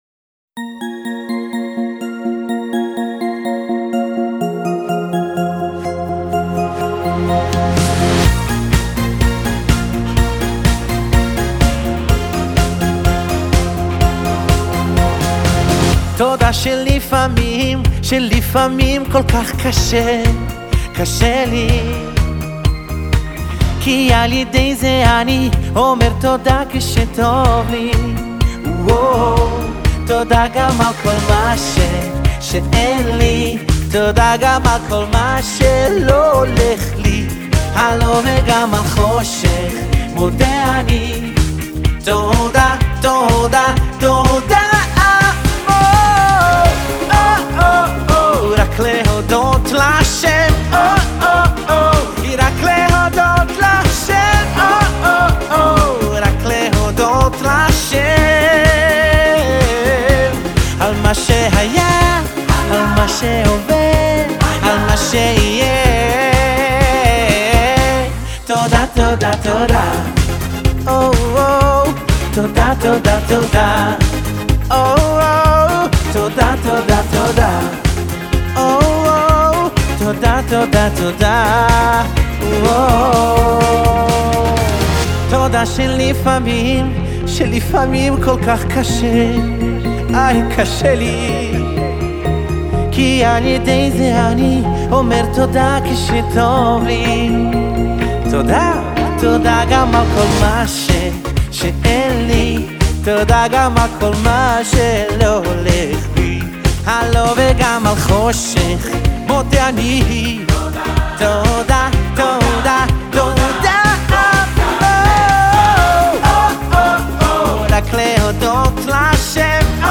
הזמר הברזילאי
ואפילו מקצבים ברזילאים סוערים וסוחפים.